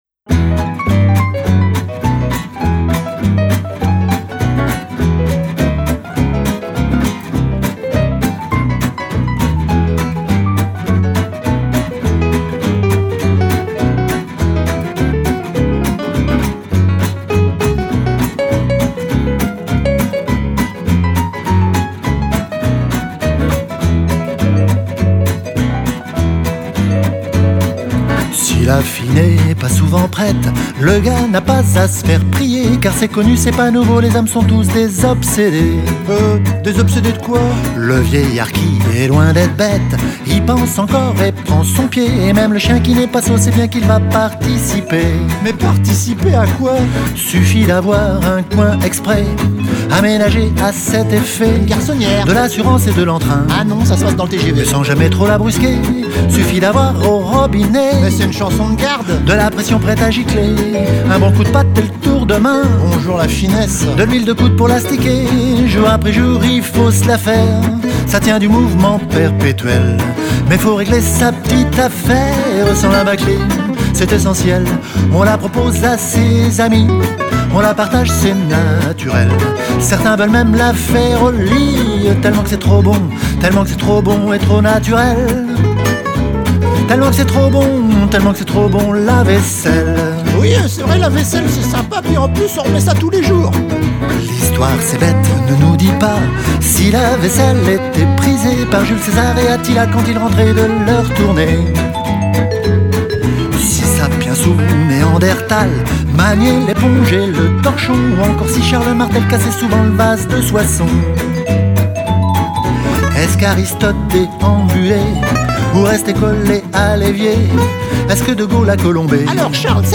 chanson française